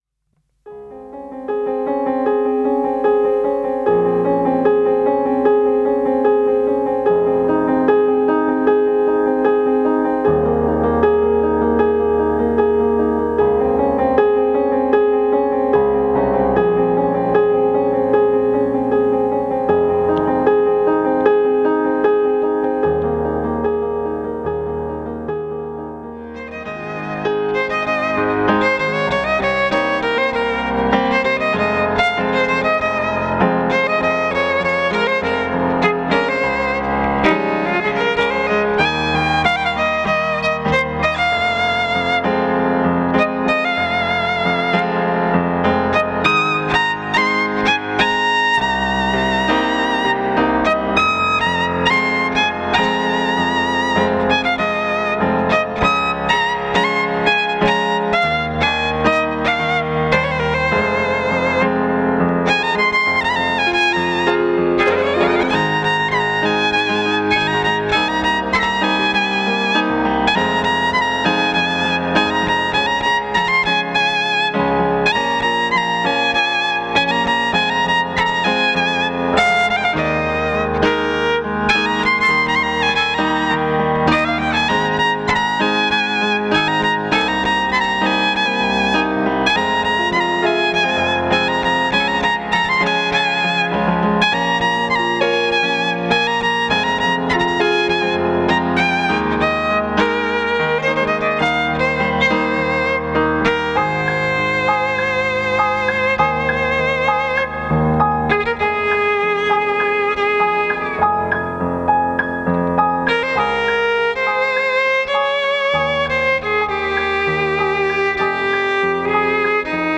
DEMO LIVE APERITIVO (Violino elettrico e piano digitale)
DEMO-LIVE-APERITIVO--Violino-elettrico-e-piano-digitale-.mp3